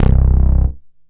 SYNTH BASS-1 0015.wav